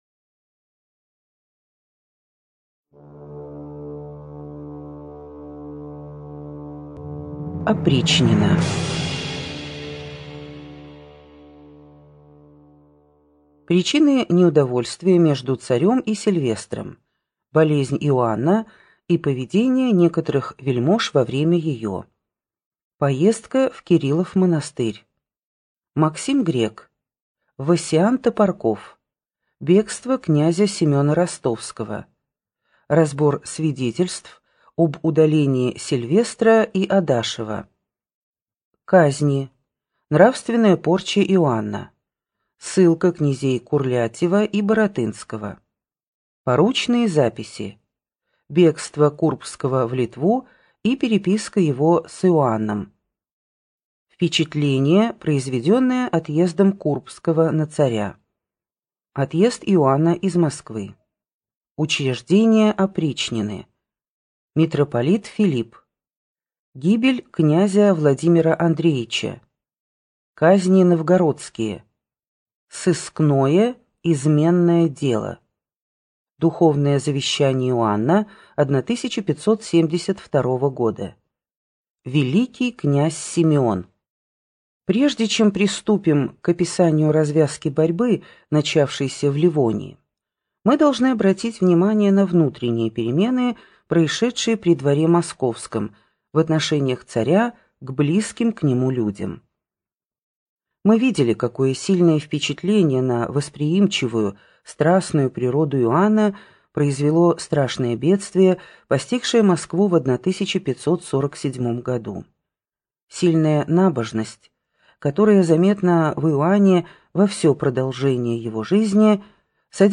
Аудиокнига Опричнина | Библиотека аудиокниг
Прослушать и бесплатно скачать фрагмент аудиокниги